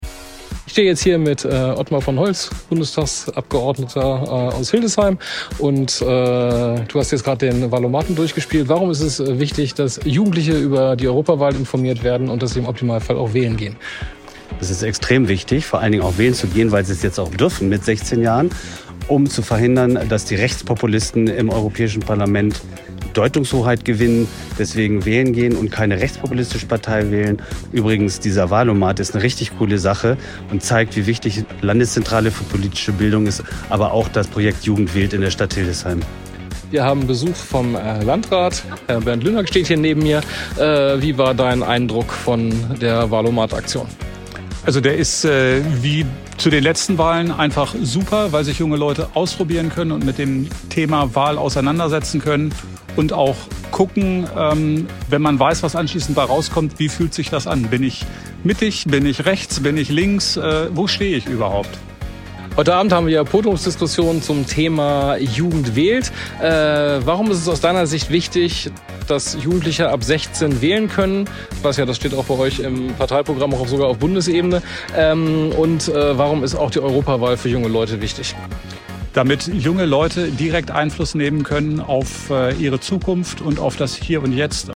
Der Landrat Bernd Lynack hat sich sehr interessiert und ausführlich über das Angebot informiert und der Bundestagsabgeordnete Ottmar von Holtz hat den Wahl-O-Mat selber ausprobiert. Hier ein paar O-Töne der beiden Politiker:
Ottmar von Holtz und Bernd Lynack im Interview© Stadt Hildesheim